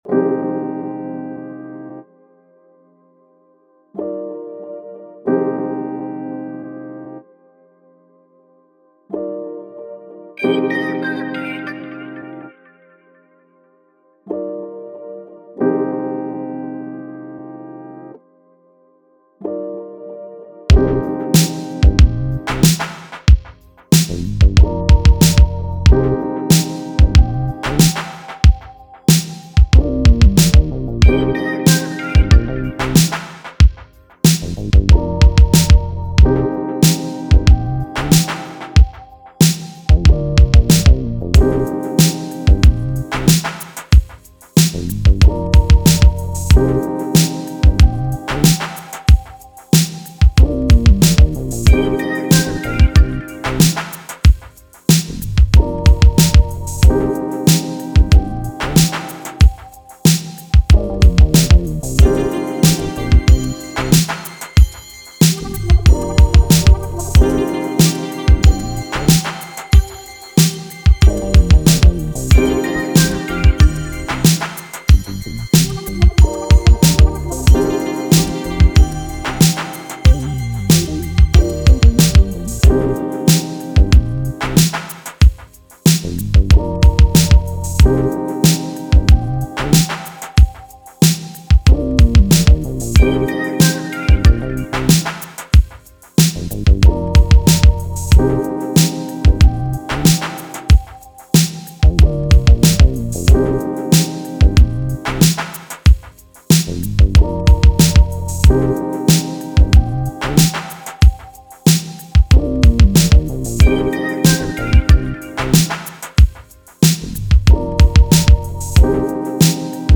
R&B, 80s, 90s
Eb Major